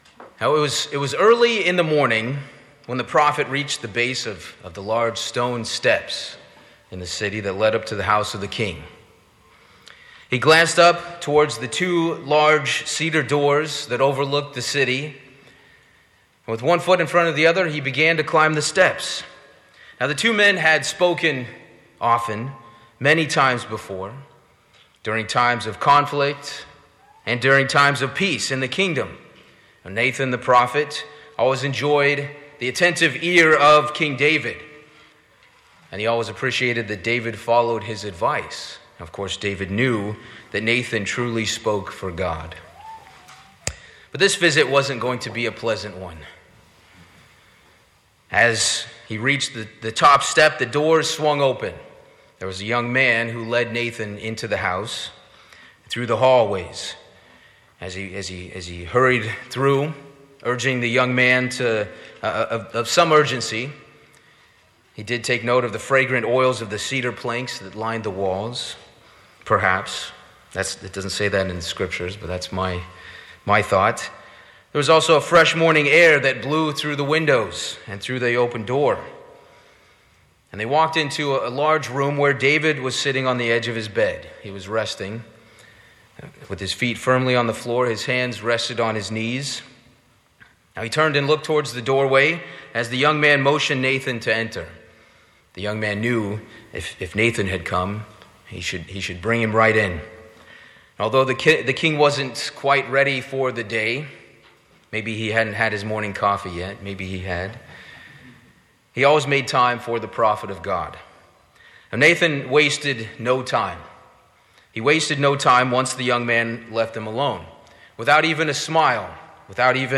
Sermons
Given in New York City, NY New Jersey - North